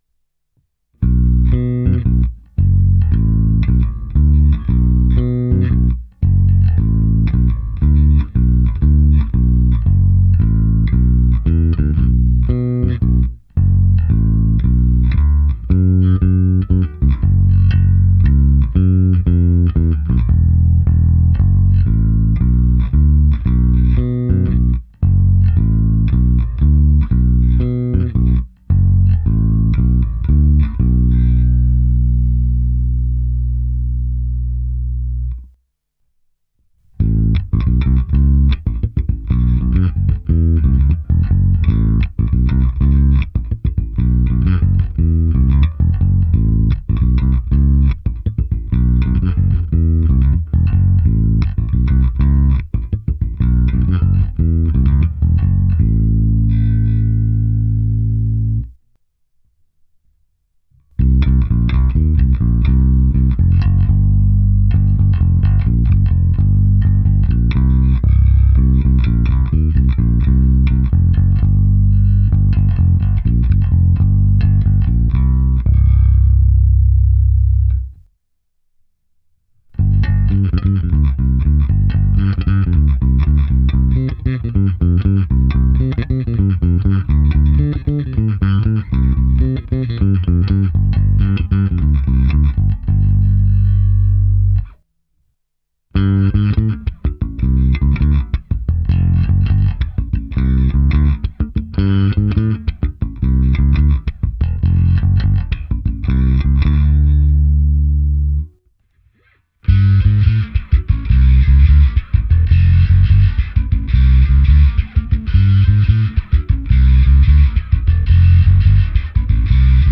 Ukázka na oba snímače přes Darkglass Harmonic Booster a Microtubes X Ultra se zapnutou simulací aparátu, v ukázce je použito i zkreslení, speciální ukázka na struně H a na konci i ukázka slapu.